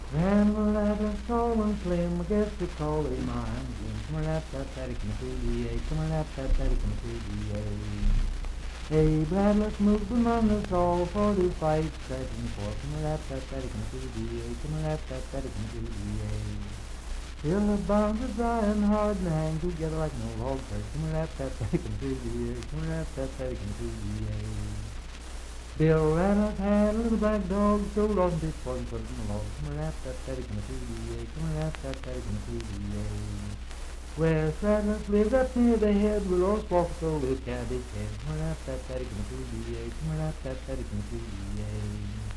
Unaccompanied vocal music
Voice (sung)
Marlinton (W. Va.), Pocahontas County (W. Va.)